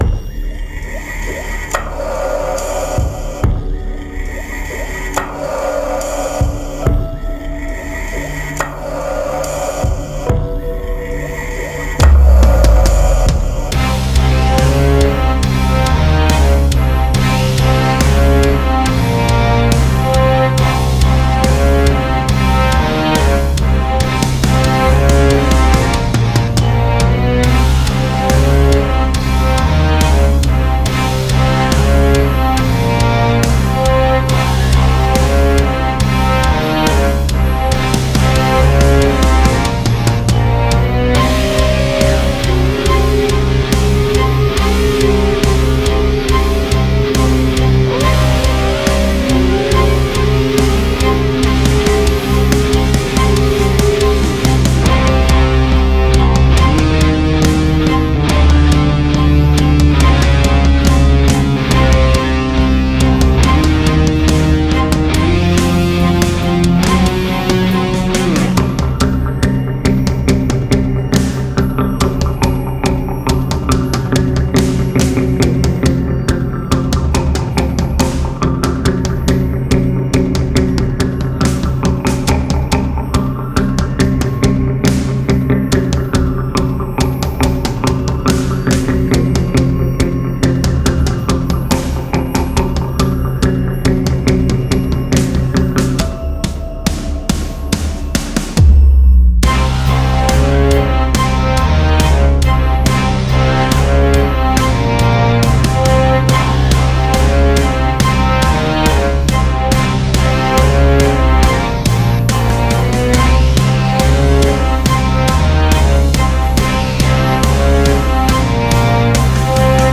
不安を煽るストリングス、歪んだギターそして低速で重く刻まれるドラムが静かでありながら逃げ場のない圧迫感を生み出します。
• BPM：70
• ジャンル：ダークシネマティック